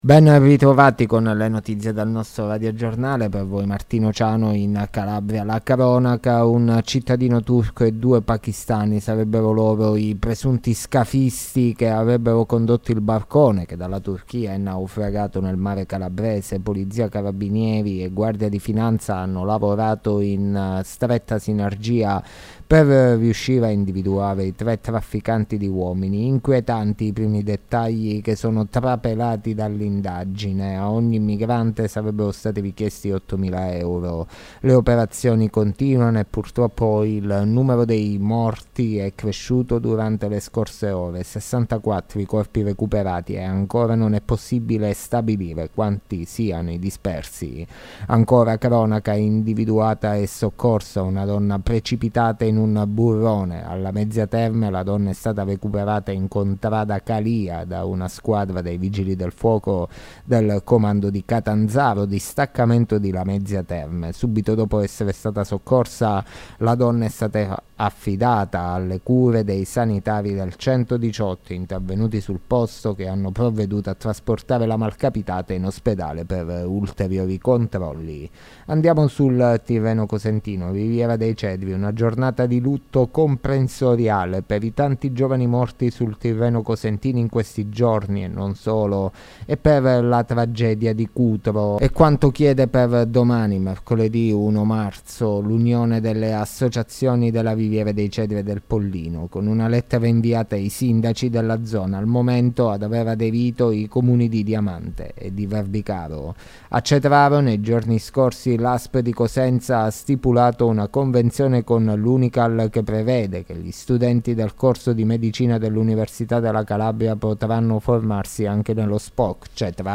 LE NOTIZIE DELLA SERA DI MARTEDì 28 FEBBRAIO 2023